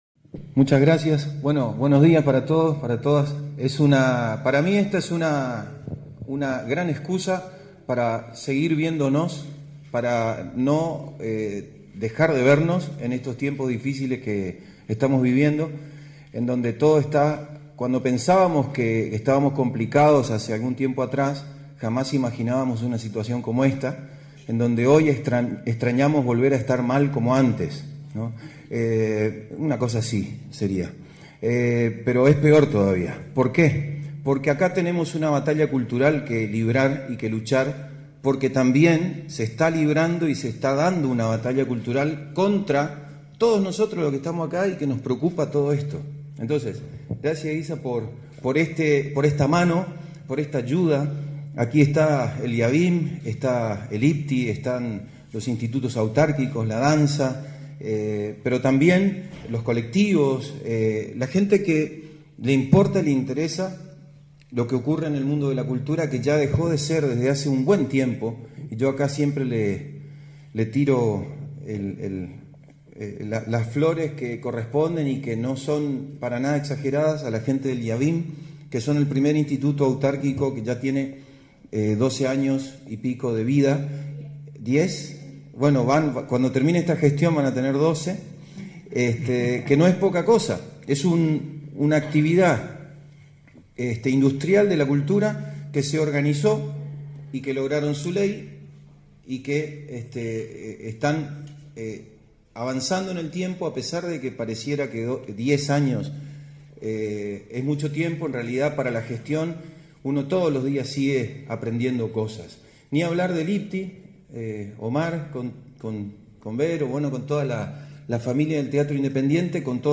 En conferencia de prensa, el ministro de la cartera, José Martín Schuap presentó el ciclo “Comunicar Cultura”, un taller teórico/práctico que brindará herramientas para el diseño de mensajes y la gestión de la comunicación.